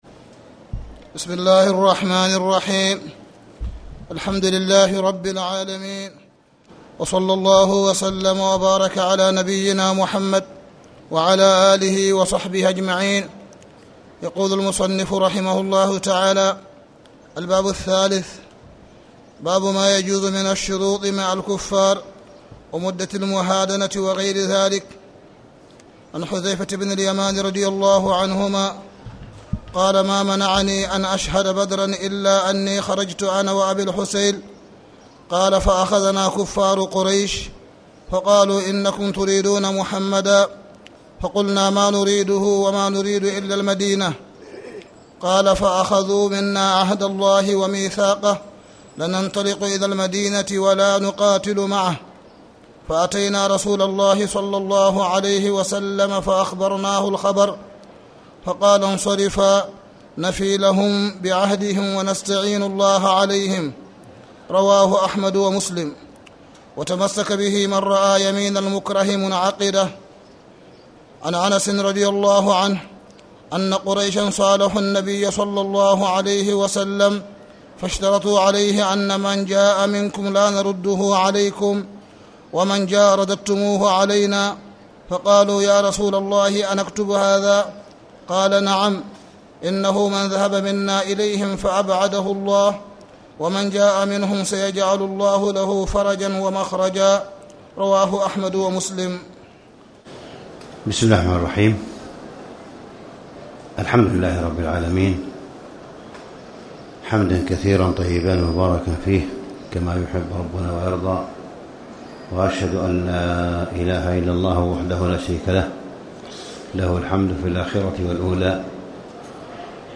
تاريخ النشر ٢٥ شعبان ١٤٣٨ المكان: المسجد الحرام الشيخ: معالي الشيخ أ.د. صالح بن عبد الله بن حميد معالي الشيخ أ.د. صالح بن عبد الله بن حميد المعاهدة مع الكفار